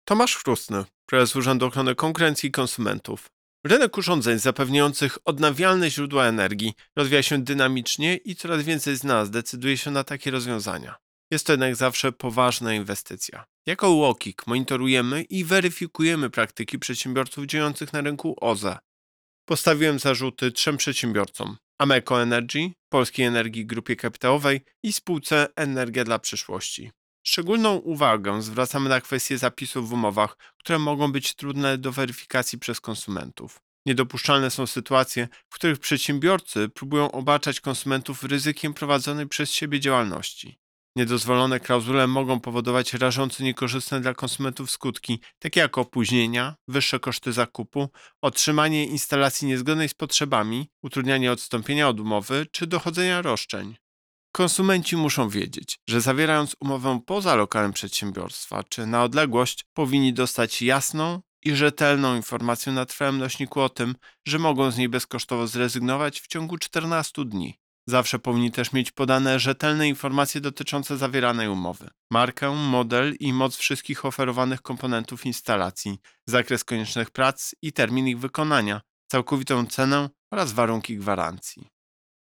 Wypowiedź Prezesa UOKiK Tomasza Chróstnego Umowy pełne pułapek Prezes Urzędu szczególną uwagę zwraca na kwestie zapisów w umowach, które mogą być trudne do weryfikacji przez konsumentów.